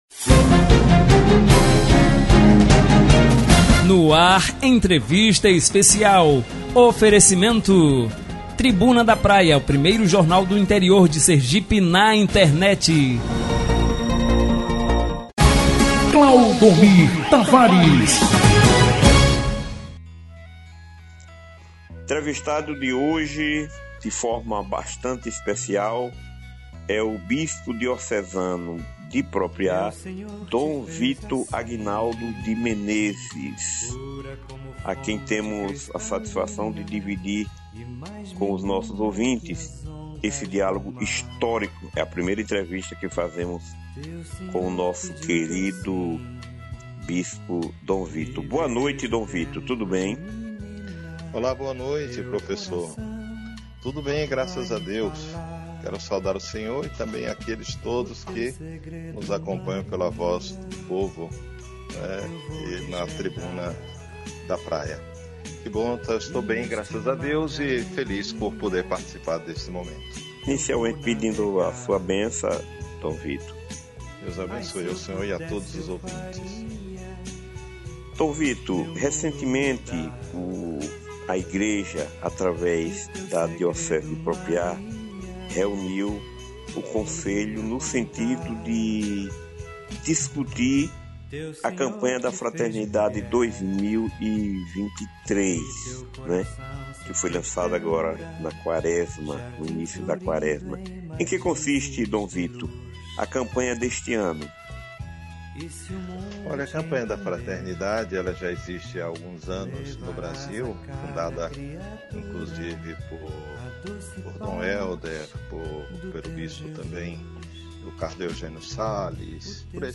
Confira abaixo na íntegra o áudio da entrevista disponibilizado pela emissora na manhã desta terça-feira, 07: